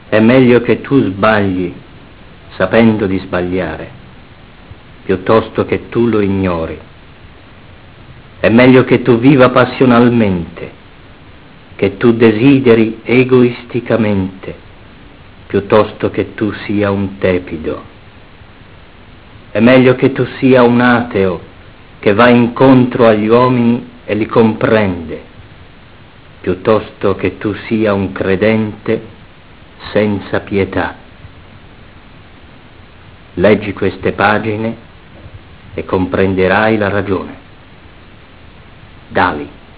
Presentazione in voce